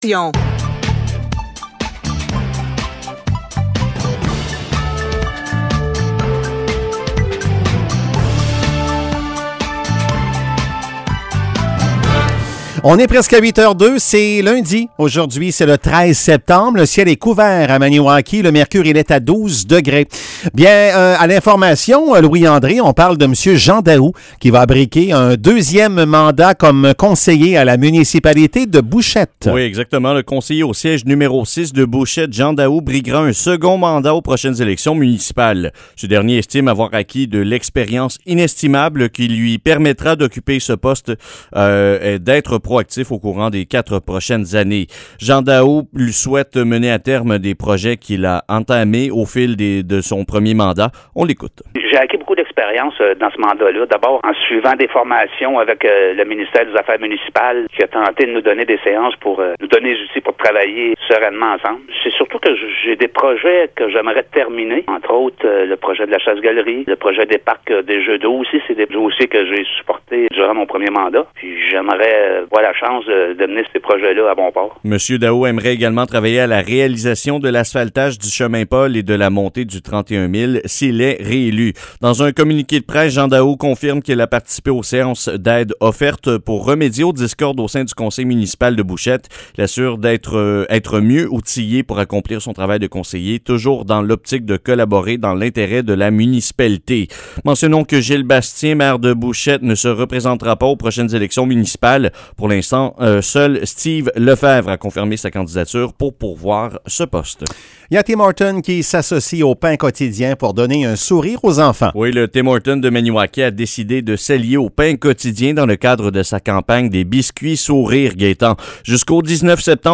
Nouvelles locales - 13 septembre 2021 - 8 h